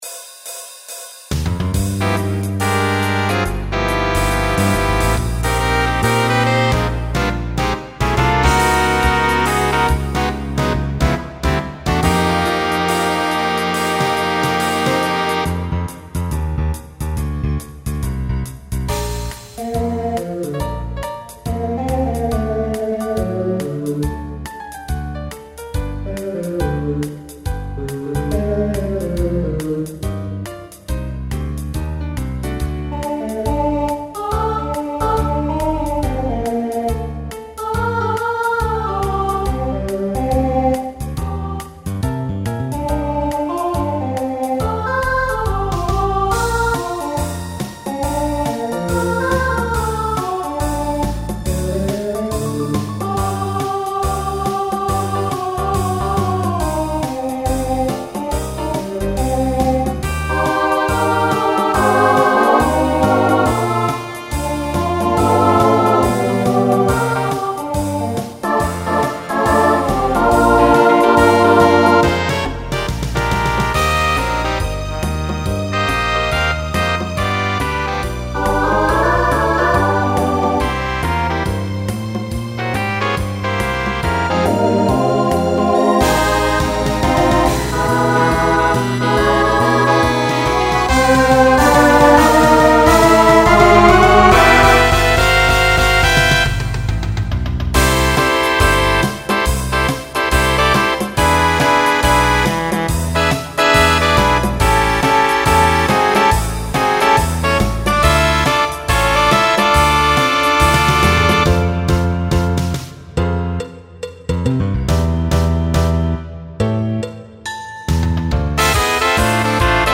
Duet to start.